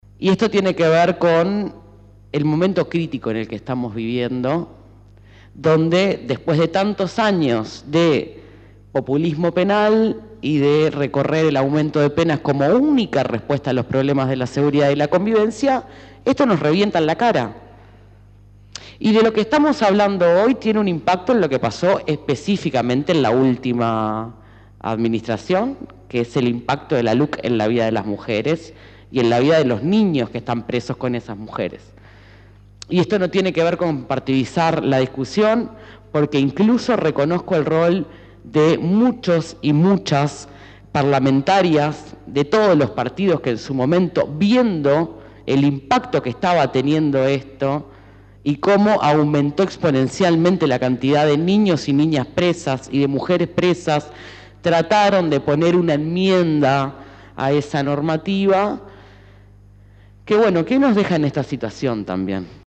En la presentación de este informe, hicieron uso de la palabra diferentes actores políticos, tanto del gobierno como legisladores del oficialismo y de la oposición.
Por su parte, la senadora del Frente Amplio, Bettiana Díaz, dijo que esto es responsabilidad de haber vivido tantos años de “populsimo penal”, explicó este término y criticó a la Luc y el impacto que esta ley tuvo en las mujeres.